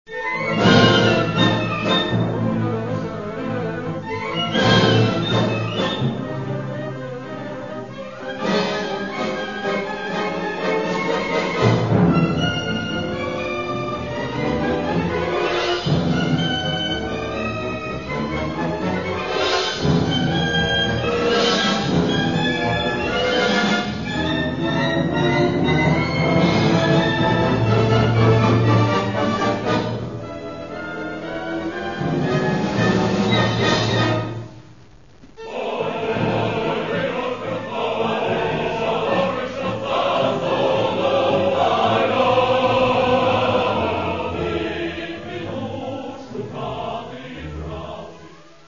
хоровые произведения, хоровые обработки народных песен и